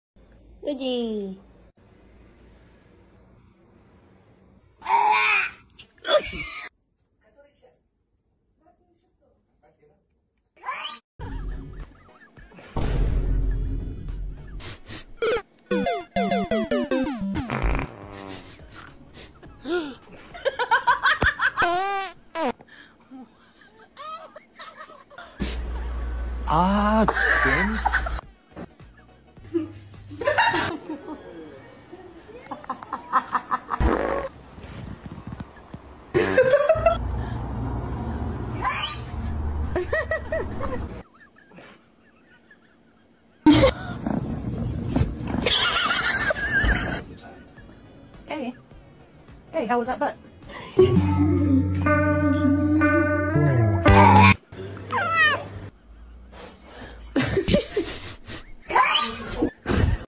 Try not nag laugh cat sound effects free download